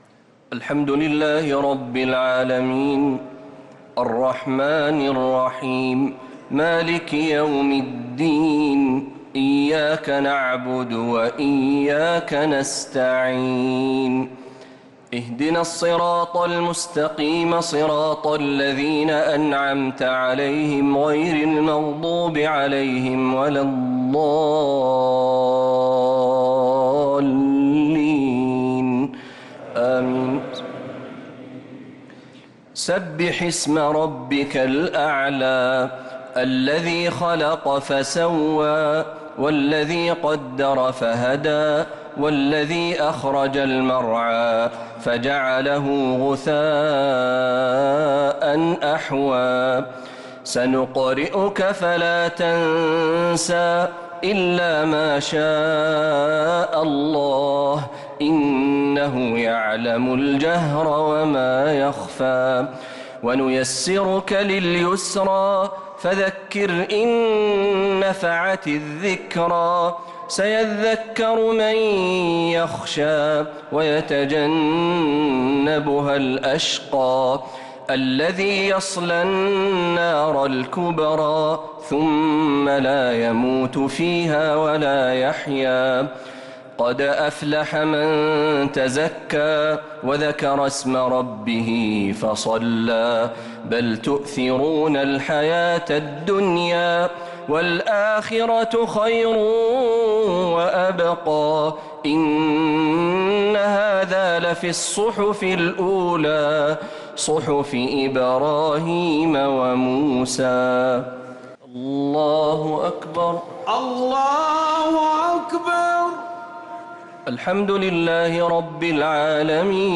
صلاة الشفع و الوتر ليلة 7 رمضان 1446هـ | Witr 7th night Ramadan 1446H > تراويح الحرم النبوي عام 1446 🕌 > التراويح - تلاوات الحرمين